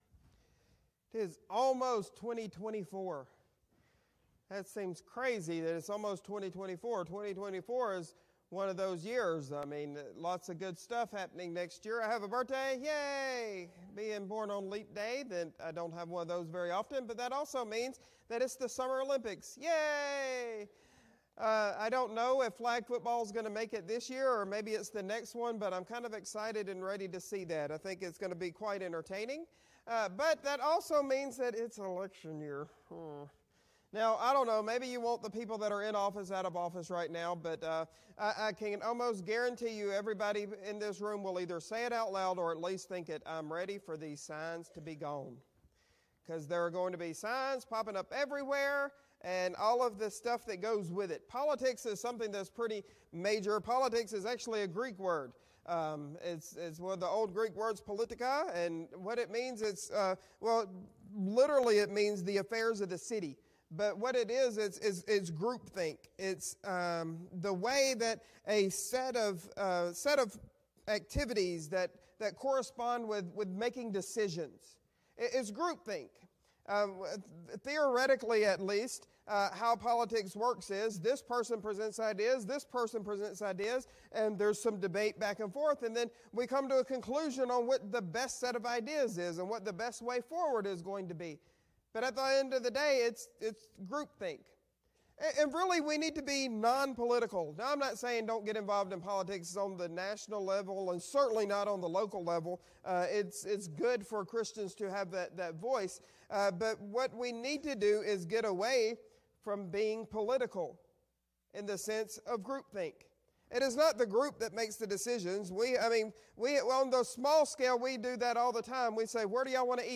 Sermons | Eastwood Baptist Church